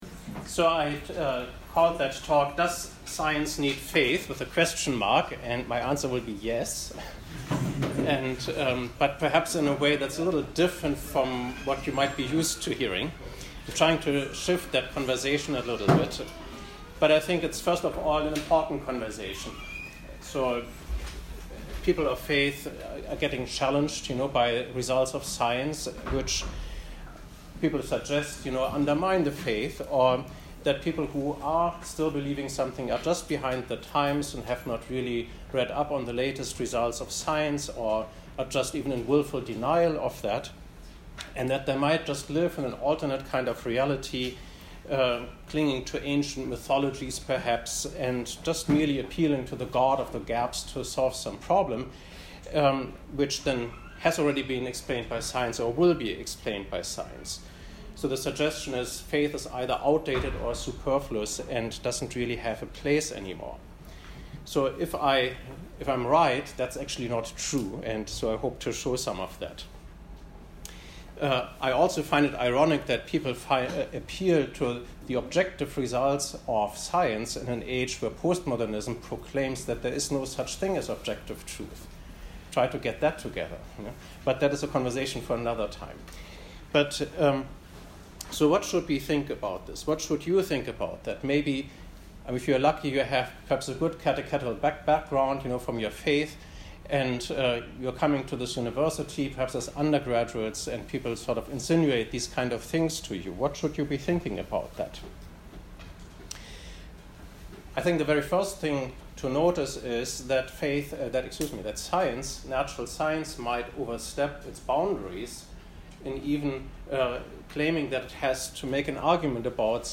This talk was offered at Yale University on October 21, 2019.